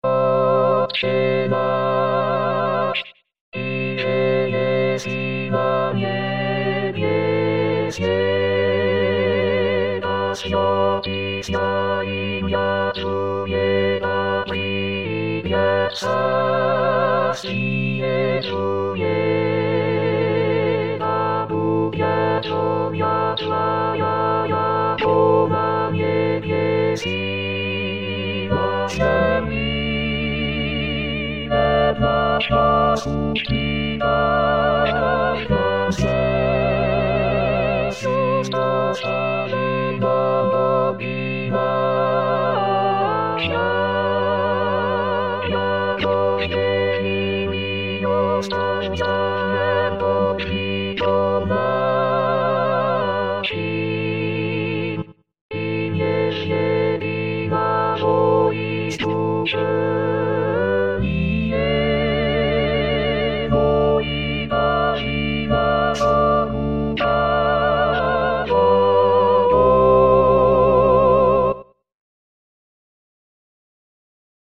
Alto.mp3